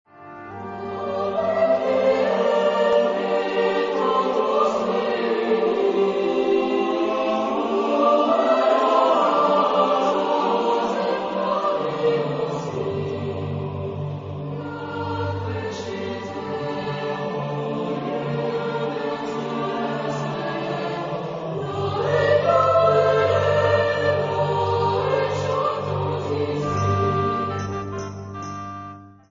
Type de choeur : SATB  (4 voix mixtes )
Instrumentation : Clavier  (1 partie(s) instrumentale(s))
Instruments : Piano (1)
Tonalité : sol mineur
Sources musicologiques : traditional French carol